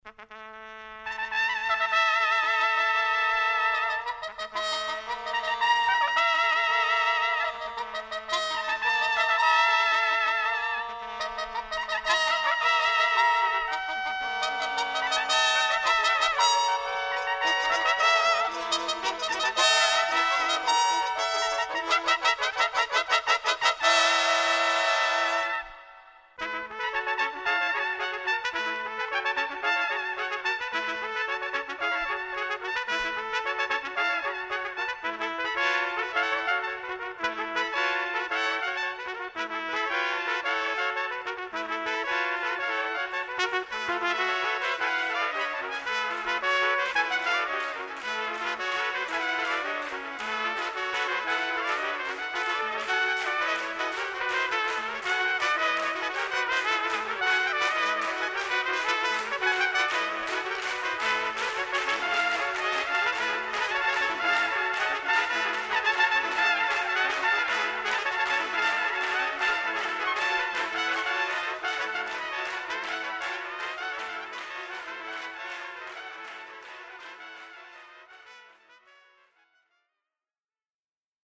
Bühnenmusik ausschließlich für Trompete